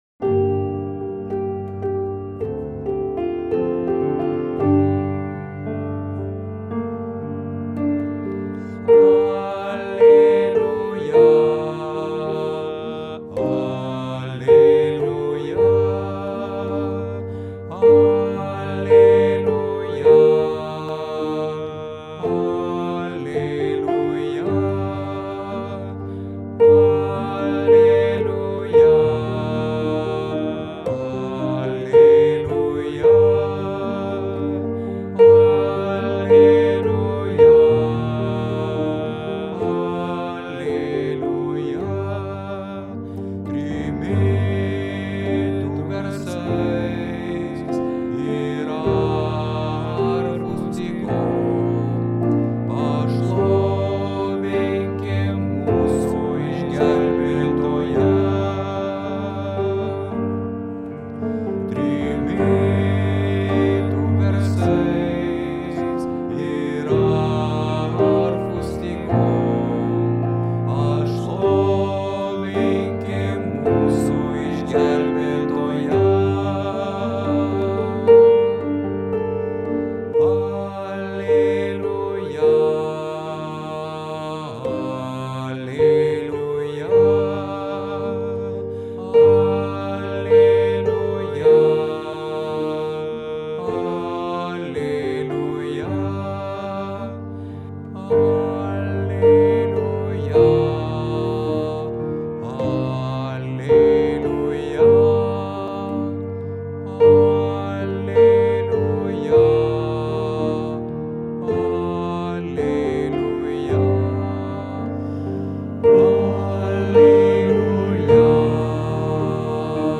Bosas: